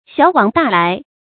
小往大來 注音： ㄒㄧㄠˇ ㄨㄤˇ ㄉㄚˋ ㄌㄞˊ 讀音讀法： 意思解釋： 本指人事的消長，后比喻商人以小牟取供利。